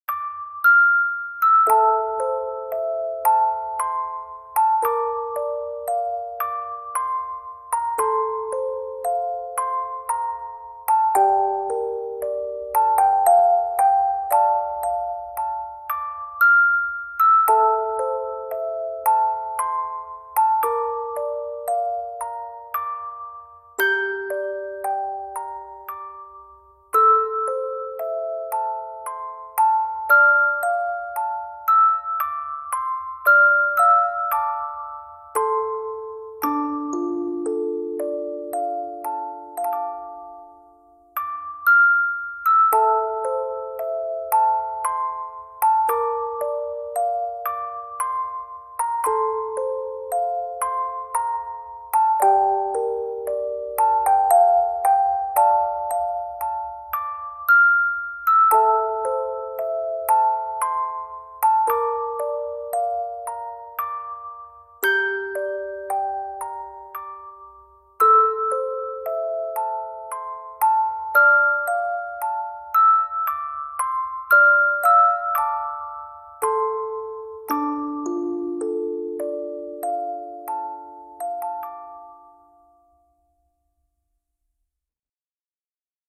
اهنگ جعبه موزیکال